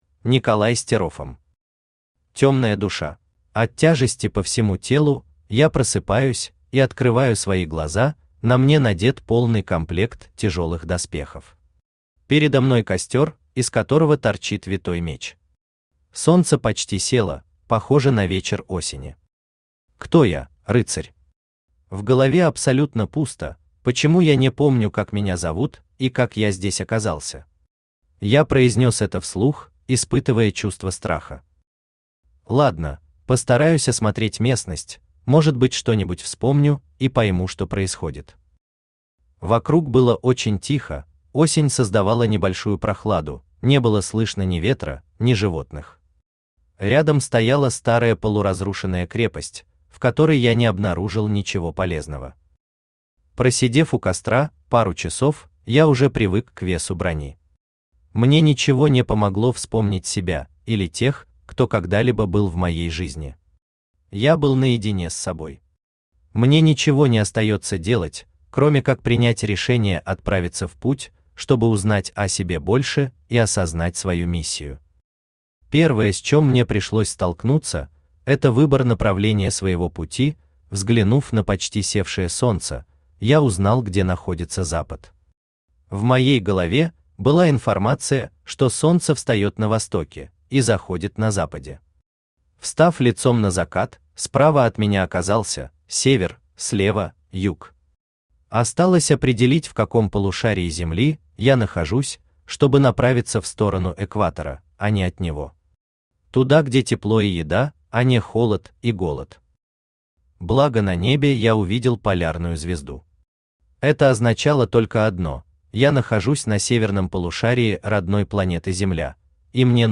Аудиокнига Тёмная душа | Библиотека аудиокниг
Читает аудиокнигу Авточтец ЛитРес.